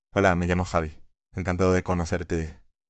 A multilingual text-to-speech model generating expressive speech with emotional tags and voice cloning capabilities.
• Human-like speech with natural intonation